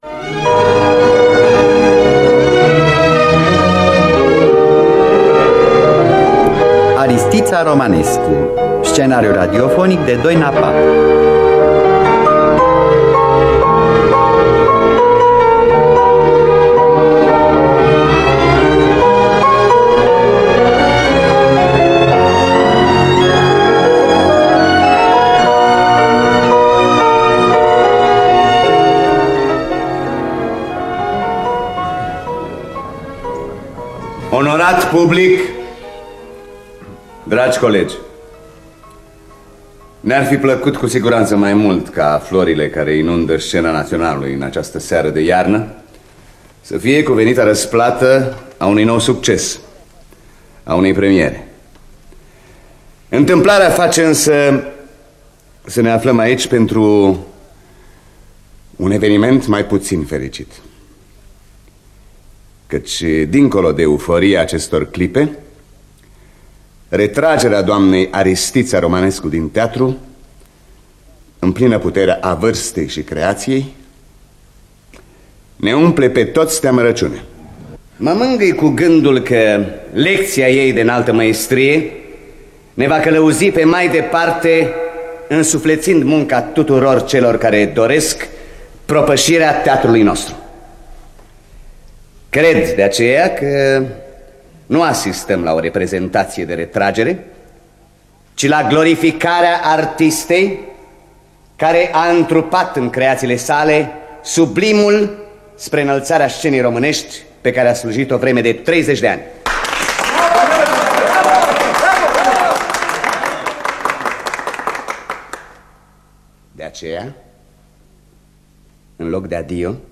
Scenariu radiofonic de Doina Papp.